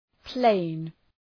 Προφορά
{pleın}